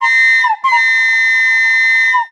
ihob/Assets/Extensions/CartoonGamesSoundEffects/Train_v1/Train_v5_wav.wav at master
Train_v5_wav.wav